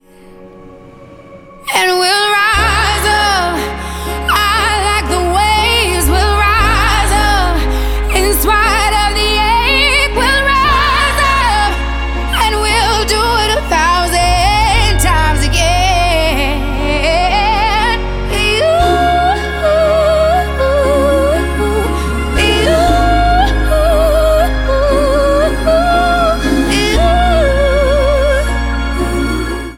• R&B/Soul